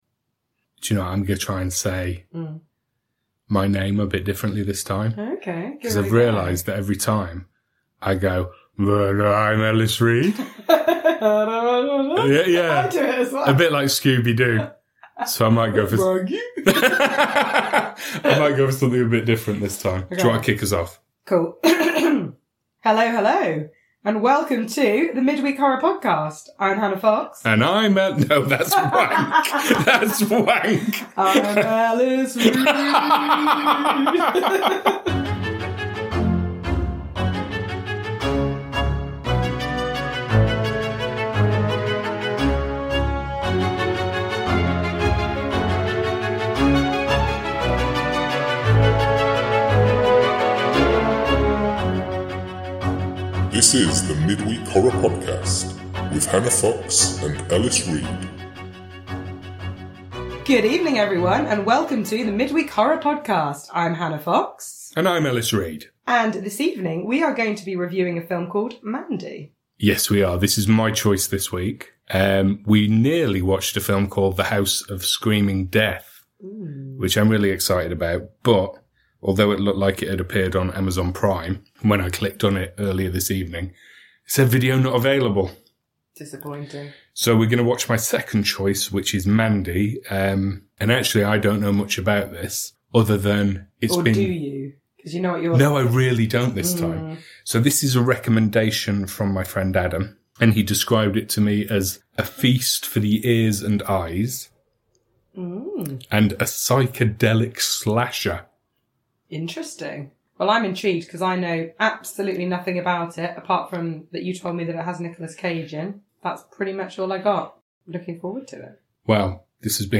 Please note: our movie reviews contain spoilers. In our fourth episode, we review Mandy and periodically burst into song.